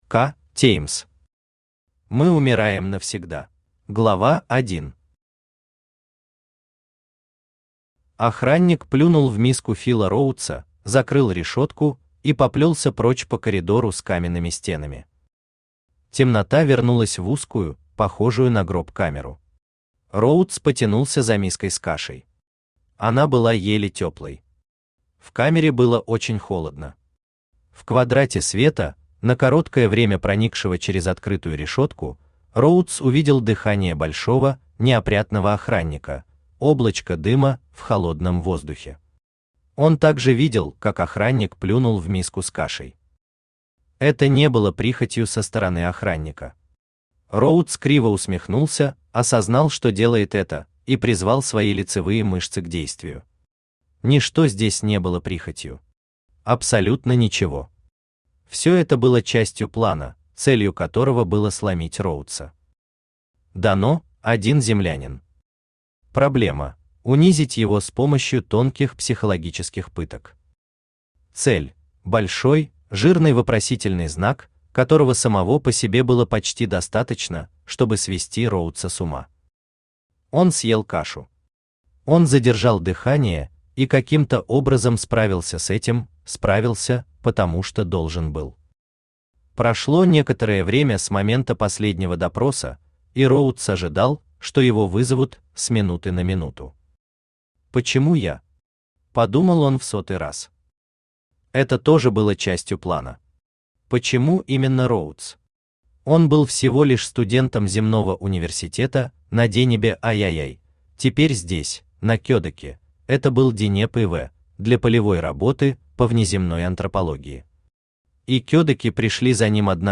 Аудиокнига Мы умираем навсегда!
Автор К. Г. Теймс Читает аудиокнигу Авточтец ЛитРес.